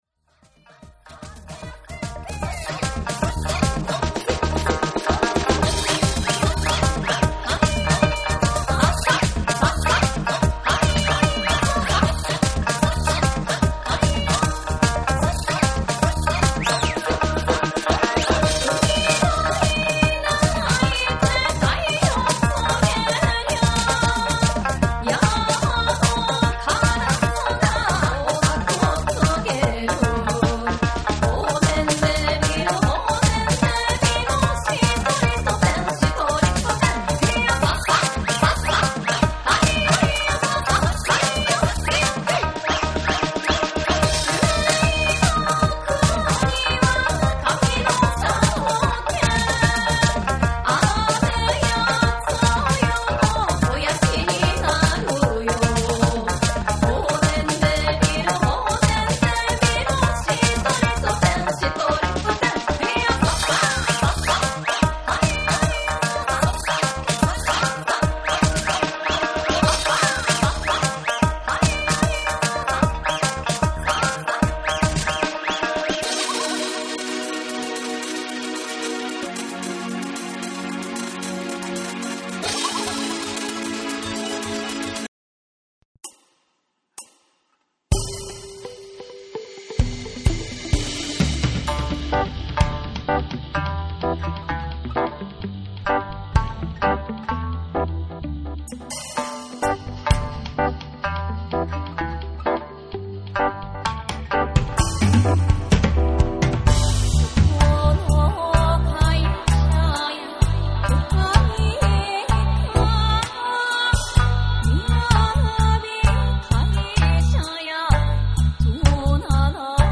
JAPANESE / ORGANIC GROOVE / NEW RELEASE(新譜)